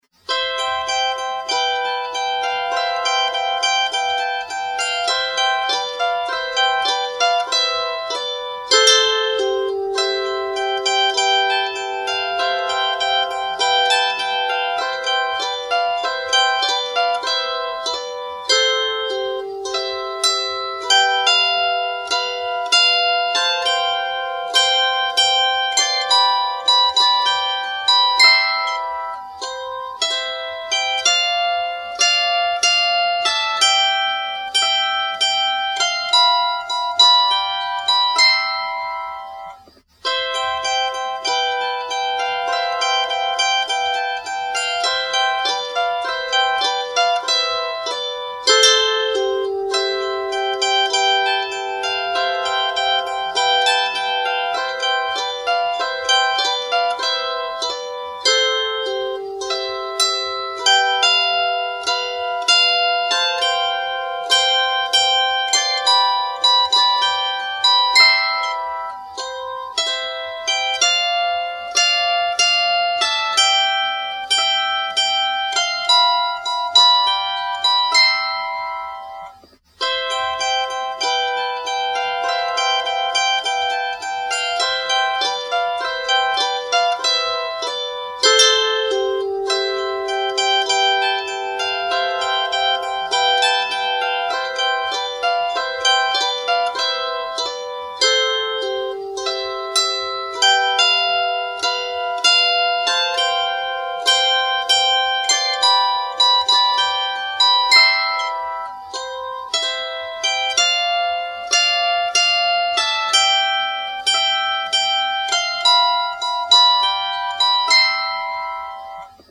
Here is a Passover song, popular at Jewish-American seders. It’s arranged for a small, 15-string zither, and you may